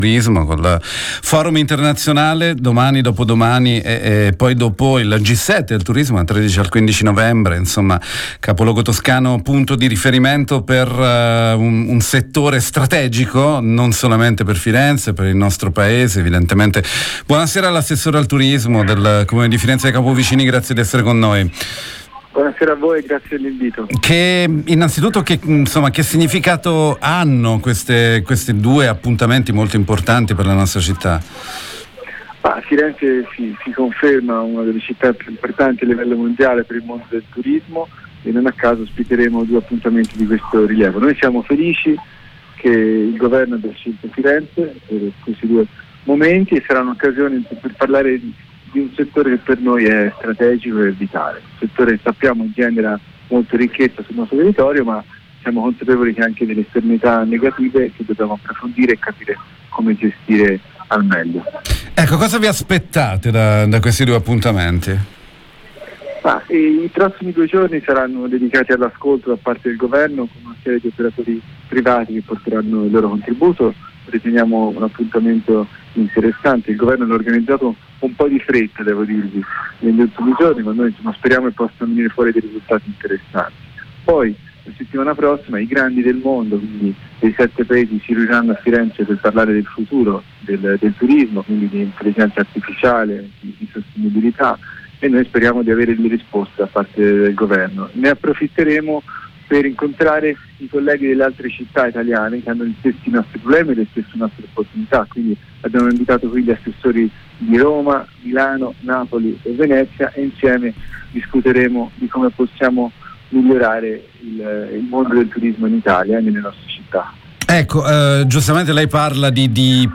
Intervista con l’assessore al turismo del Comune di Firenze, Jacopo Vicini